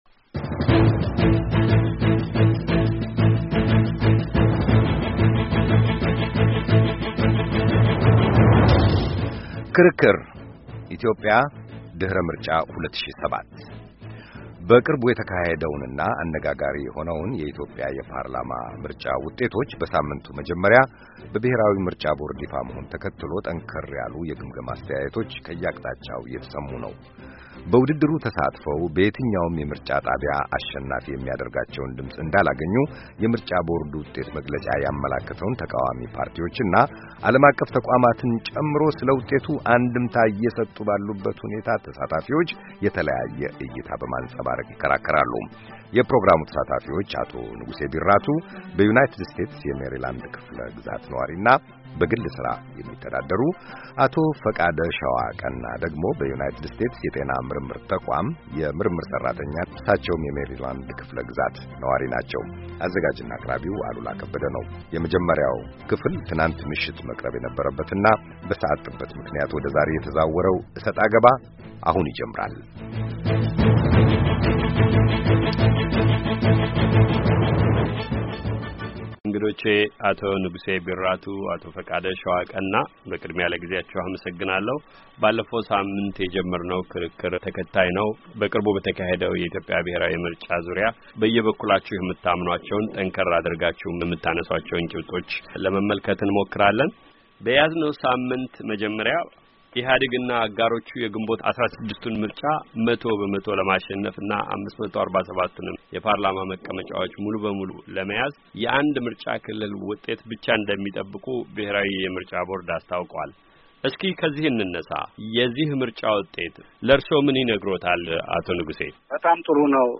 ክርክር:- ምርጫ 2007 ውጤቱ፥ አንድምታና የወደፊቱ አቅጣጫዎች፤